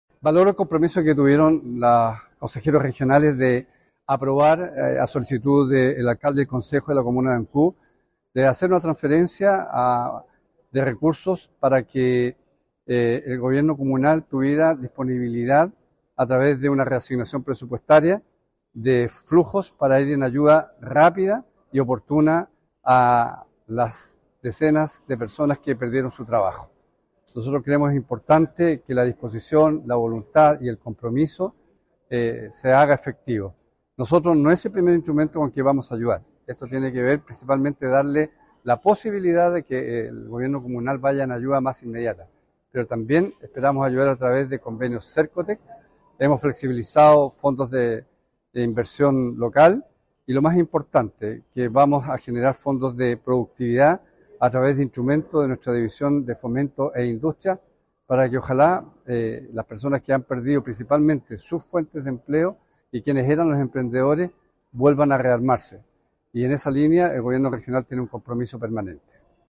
Posterior a la transferencia, el municipio realizará las modificaciones presupuestarias necesarias para distribuir la ayuda a los damnificados, iniciativa que subrayó el gobernador regional Alejandro Santana.